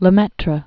(lə-mĕtrə), Georges Henri or Édouard 1894-1966.